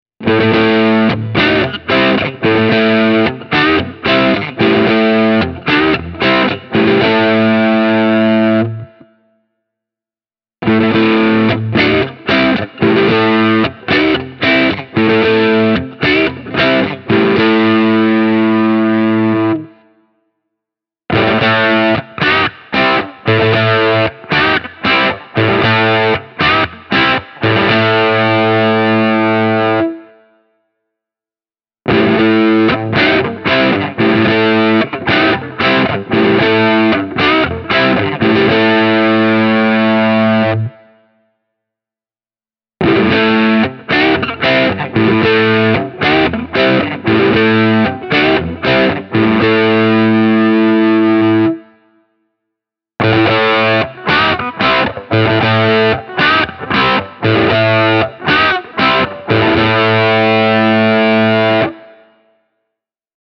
In these soundbites you get the split humbucker selections first (neck, both, bridge) followed by the full humbuckers:
Schecter Solo-6 Custom – overdriven